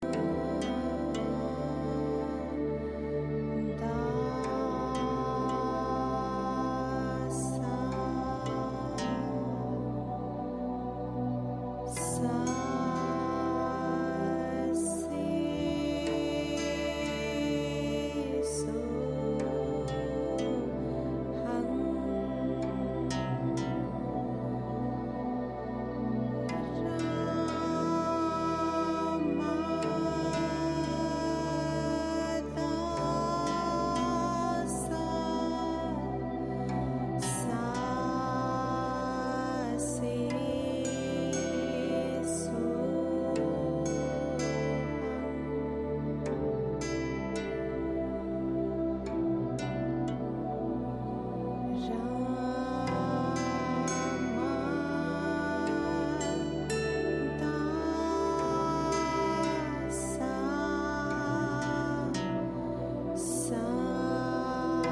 Deep Meditative journey
chants and mantras